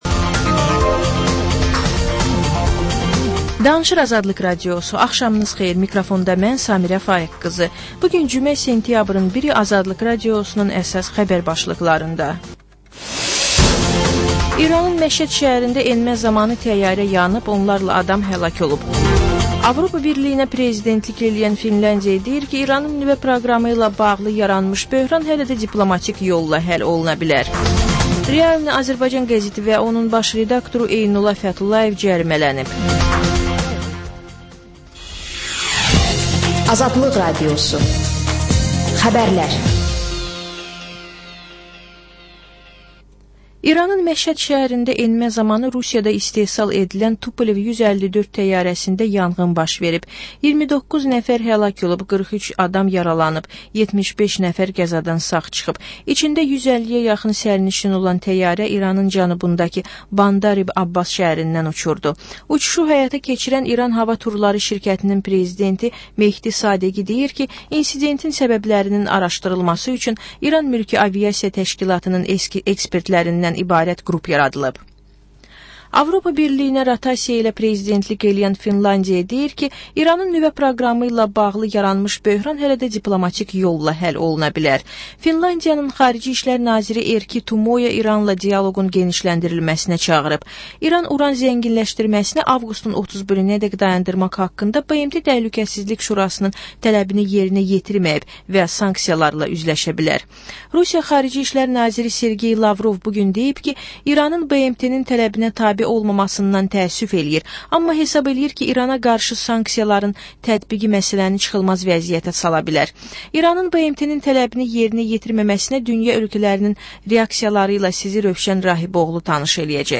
Xəbərlər, reportajlar, müsahibələr. Və: Günün Söhbəti: Aktual mövzu barədə canlı dəyirmi masa söhbəti.